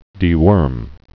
(dē-wûrm)